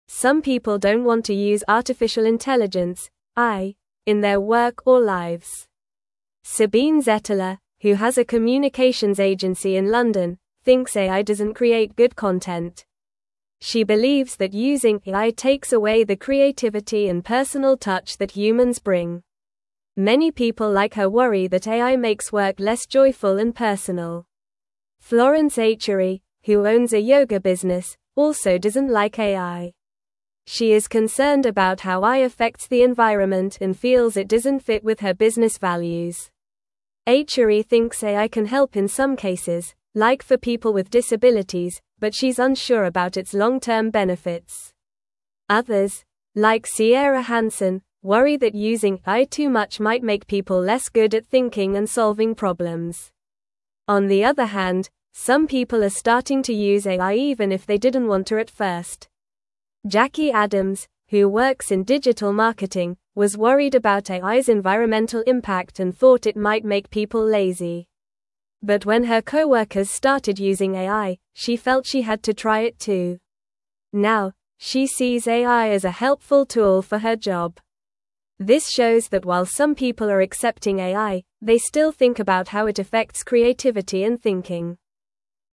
Normal
English-Newsroom-Lower-Intermediate-NORMAL-Reading-People-Worry-About-AI-and-Creativity-and-Connection.mp3